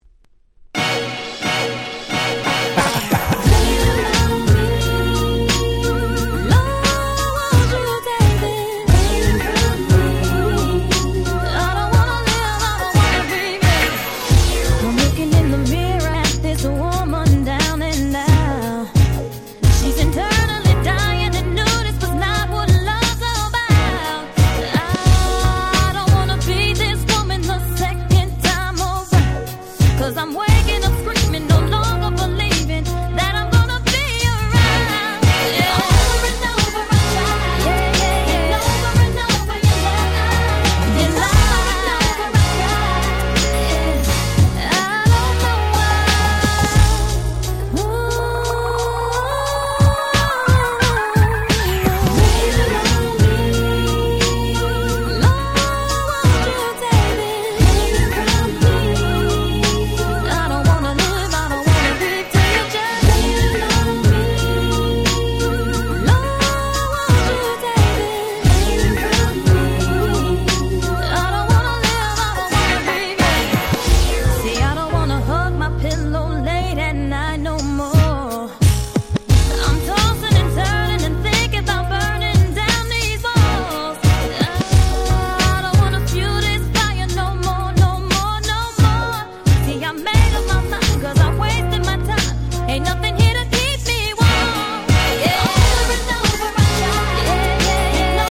03' Smash Hit R&B !!